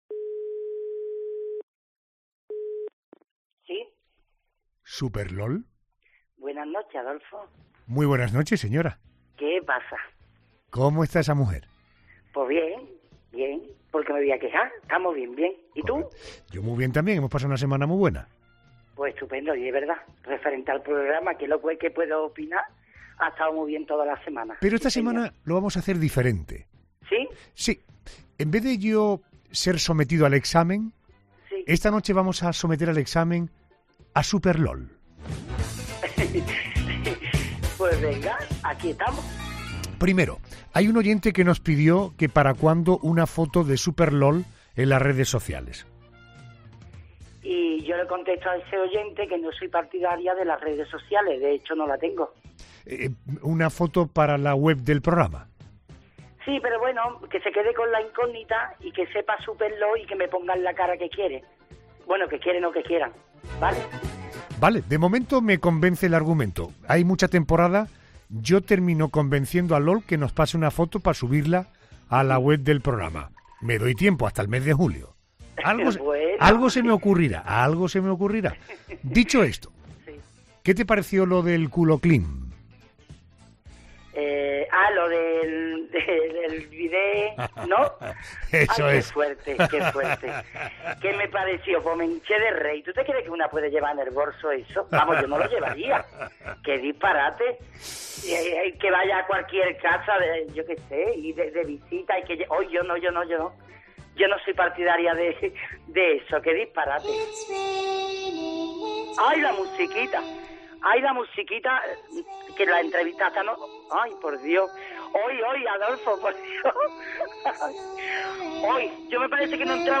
Cuando nuestra crítica menos se lo esperaba sonaba la nana terrorífica que como no podía ser de otra forma a ella tampoco le ha gustado mucho.